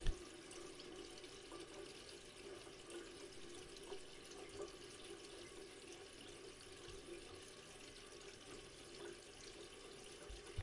描述：这是一种水的声音。这是一个前景声音。这是记录在浴室里，我们可以欣赏水龙头的水滴声，它是用录音机变焦H4n录制的。
Tag: 分出 水龙头